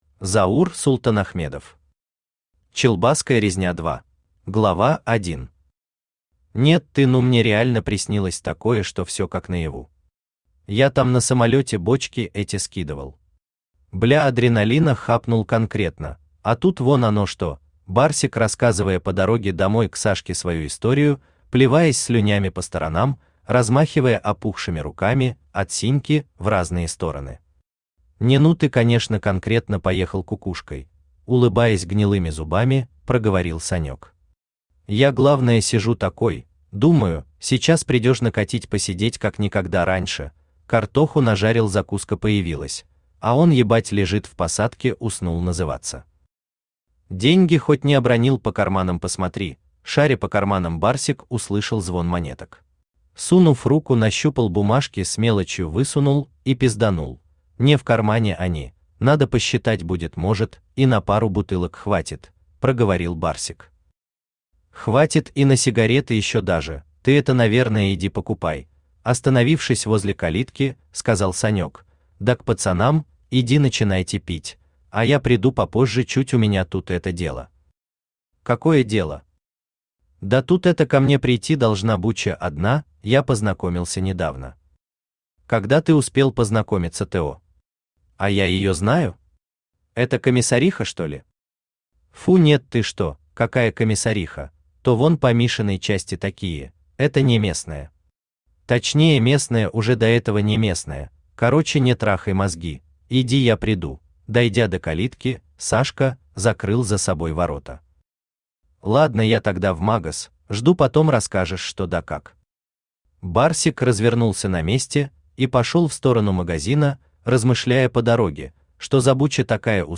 Аудиокнига Челбаская резня 2 | Библиотека аудиокниг
Читает аудиокнигу Авточтец ЛитРес.